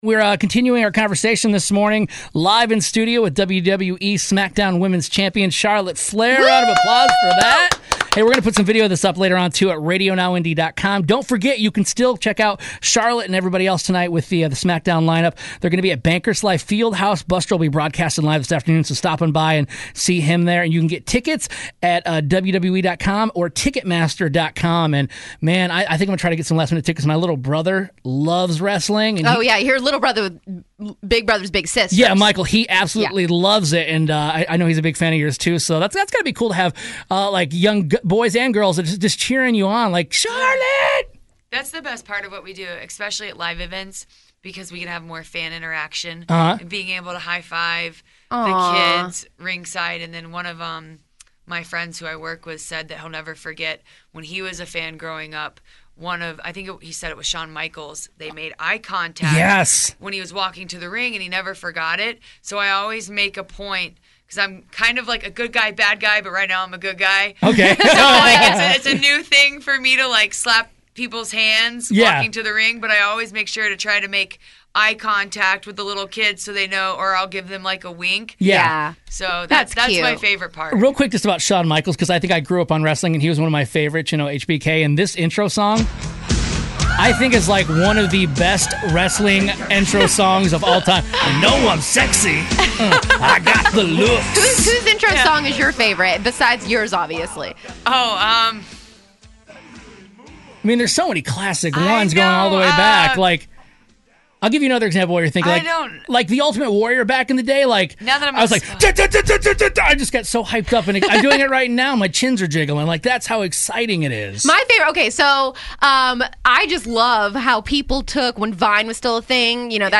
Charlotte Flair In Studio - Part Two